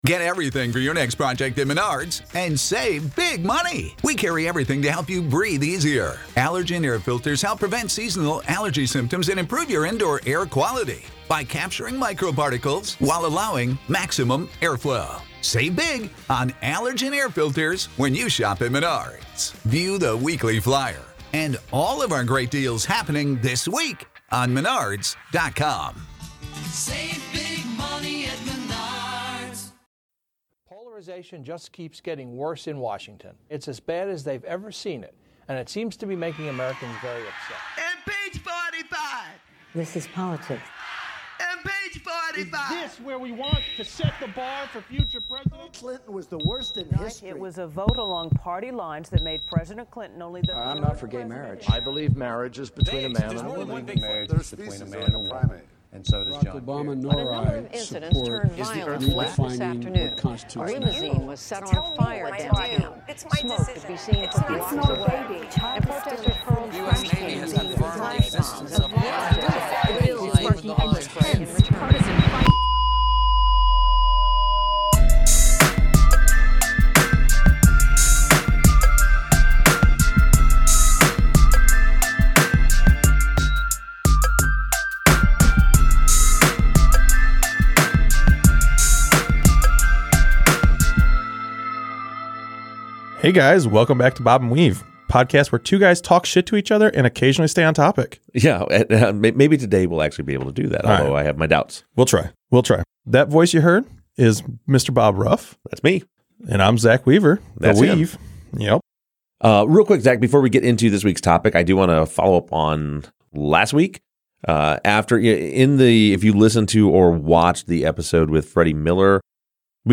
What seemed like a simple topic, actually gets pretty muddy as the conversation progresses. Emotions were high by the end of the episode.